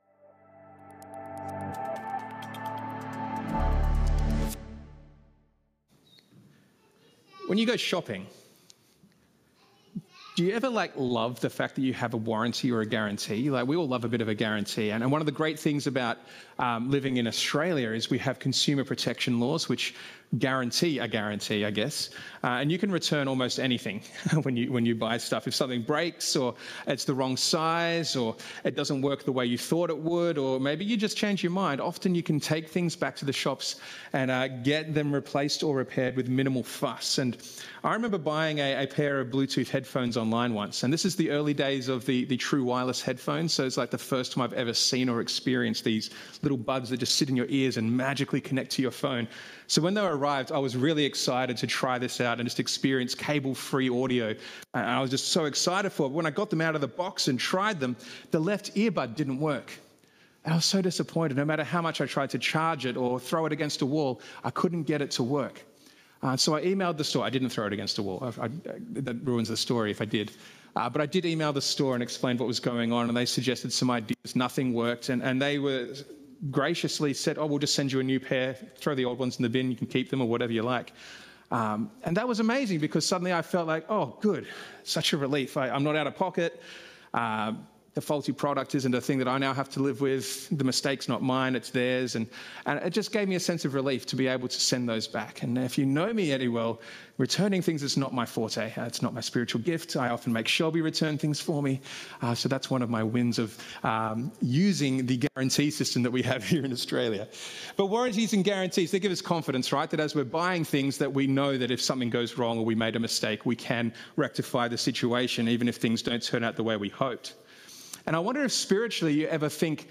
Sermons - Como Baptist Church